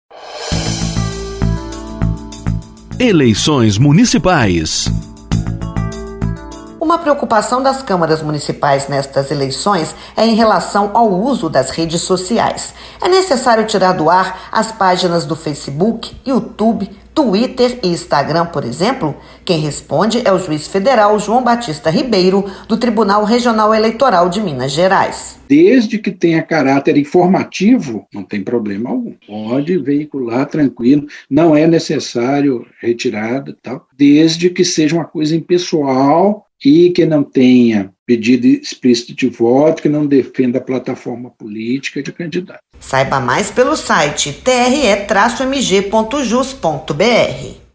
O Juiz Federal, João Batista Ribeiro, esclarece o uso de Facebook, Twitter, Instagram e outras redes para divulgar o trabalho da câmara municipal no período de campanha.